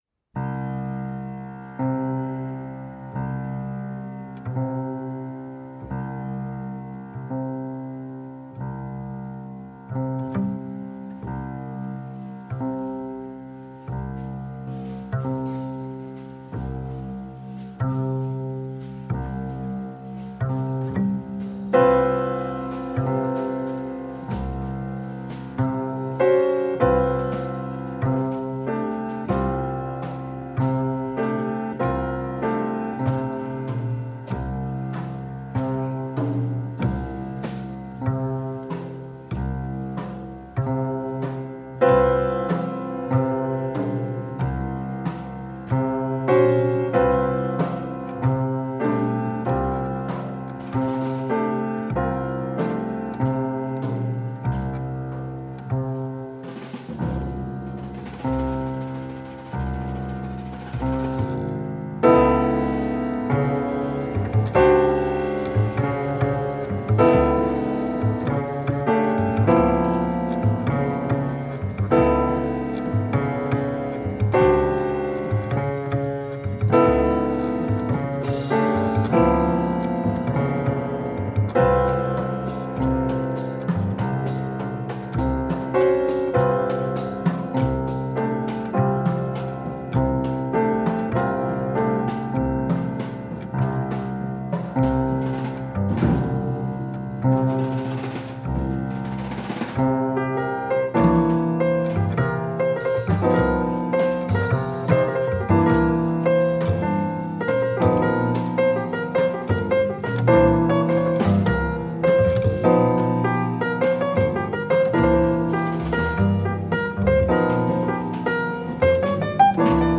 Genre: Jazz
Style: Hard Bop, Contemporary Jazz, Free Jazz